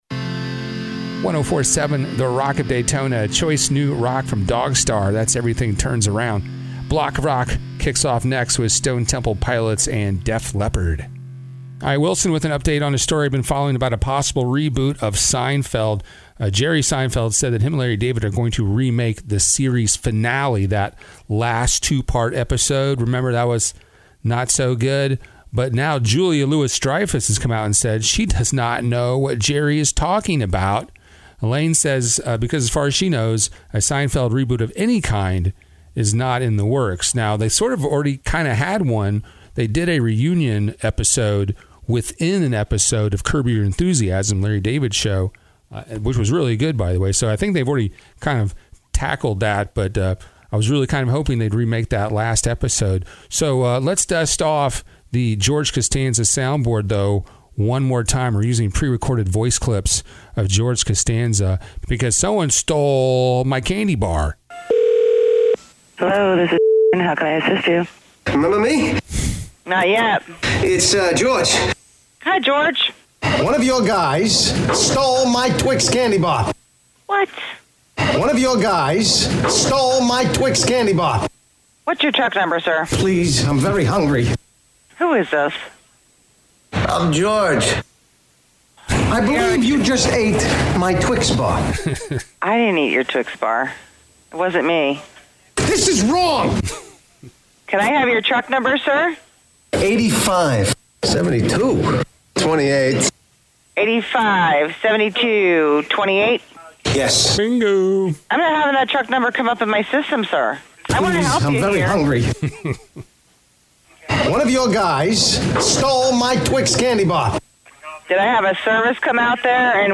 Voice Tracking Demo